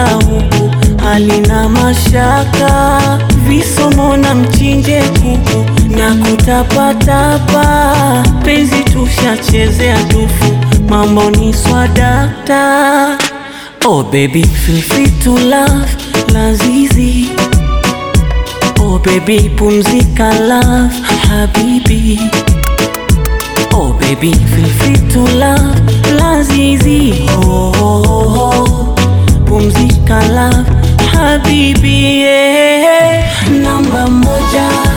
Жанр: Поп / Африканская музыка
# Afro-Pop